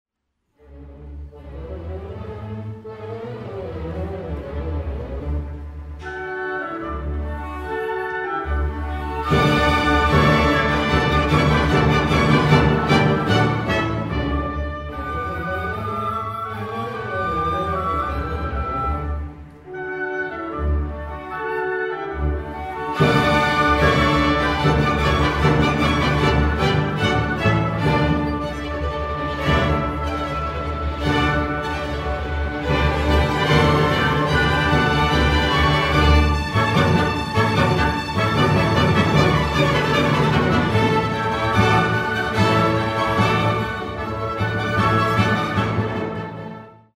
1-Obertura-las-Bodas-de-Figaro-b.mp3